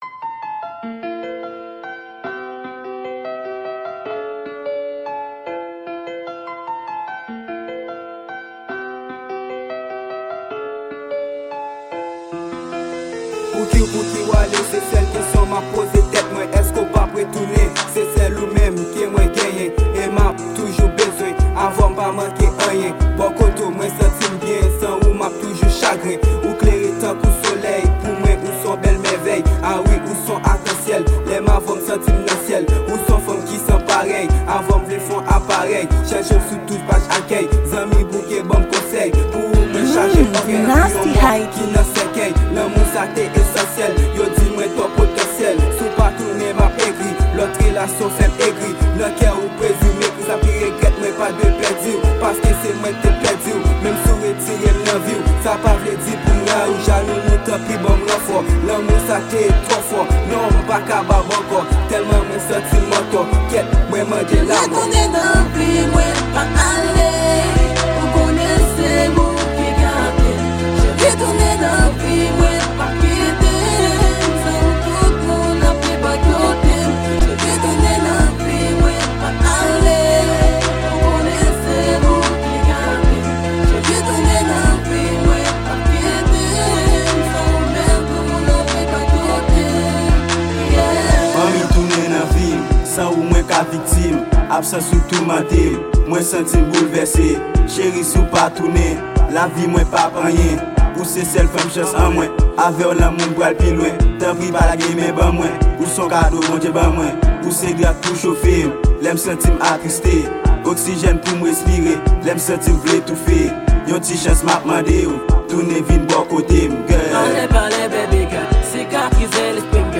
Genre: Rap-RnB.